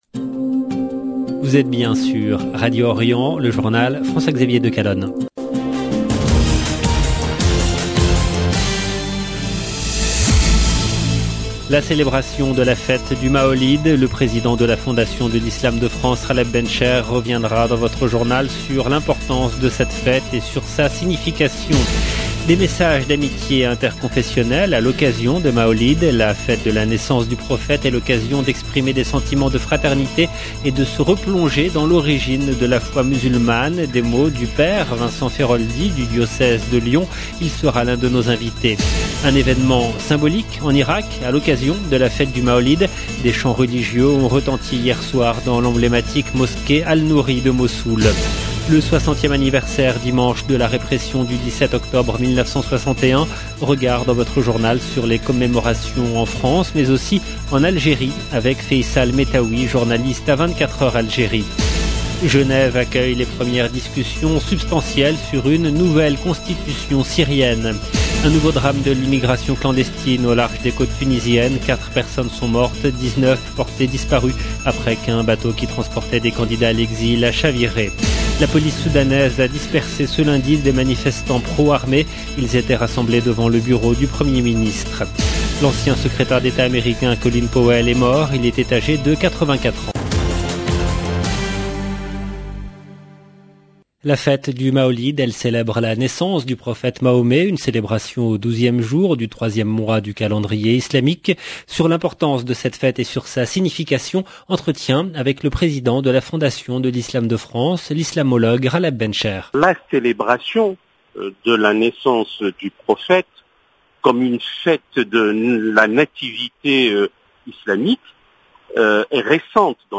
Le journal du soir en langue française du 18/10/21